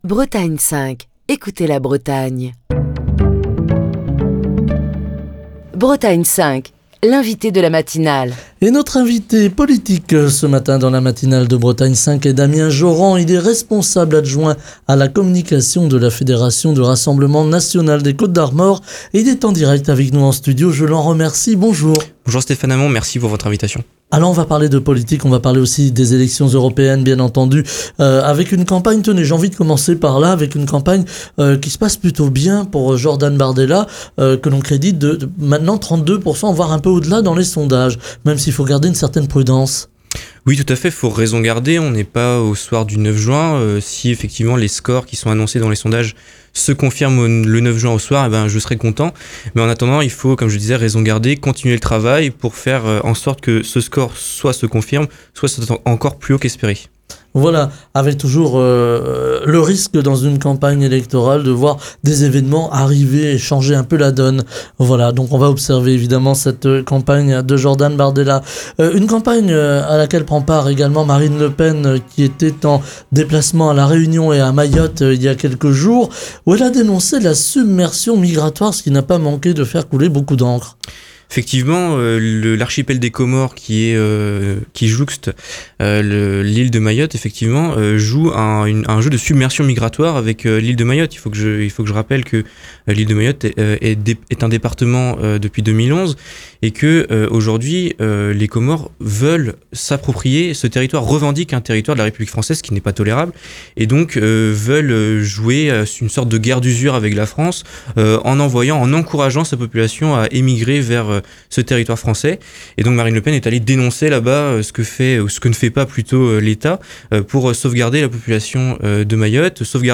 Au sommaire de cet entretien, les européennes du 9 juin et les grands thèmes portés par la liste de Jordan Bardella, dans une campagne électorale qui se déroule dans un climat loin d'être apaisé.